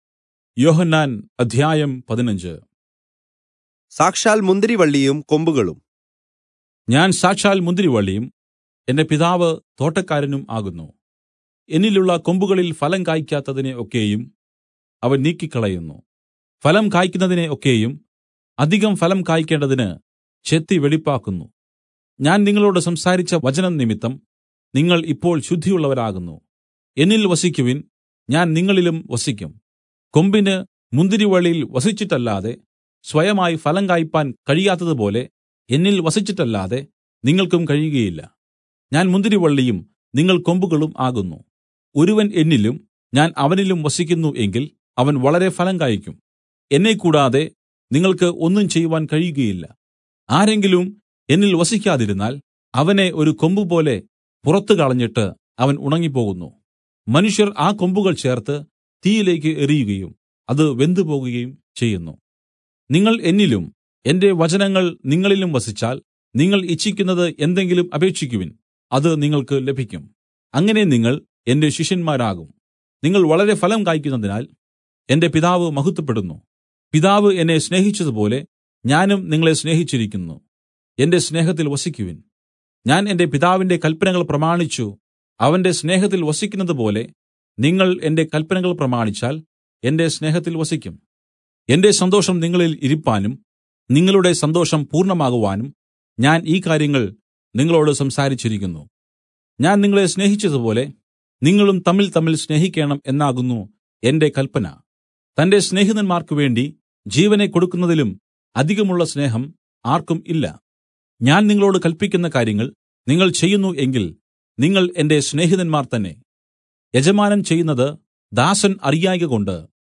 Malayalam Audio Bible - John 6 in Irvml bible version